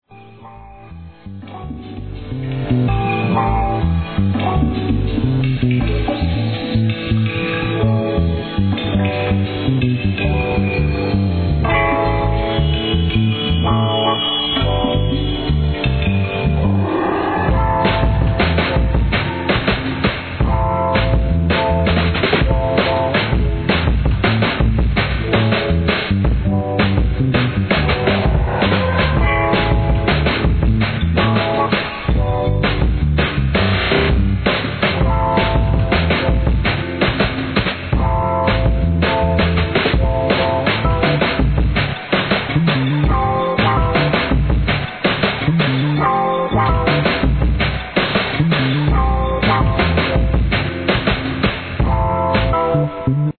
HIP HOP/R&B
今回も極上のJAZZYダウンテンポ・ブレイクビーツ!!